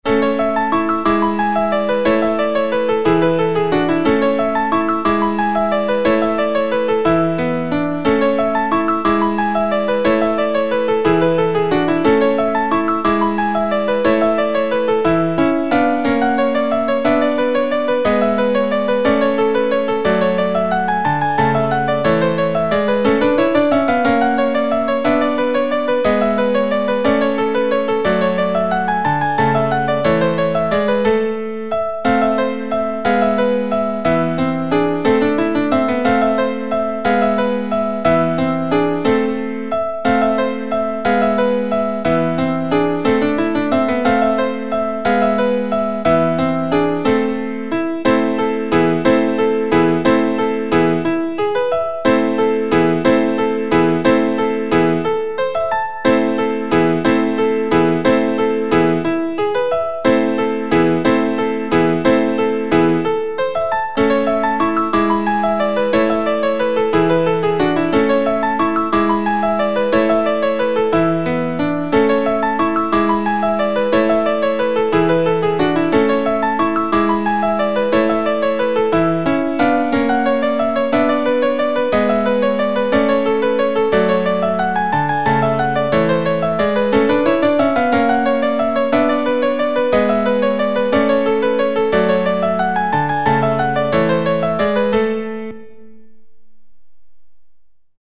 No parts available for this pieces as it is for solo piano.
Piano  (View more Easy Piano Music)
Classical (View more Classical Piano Music)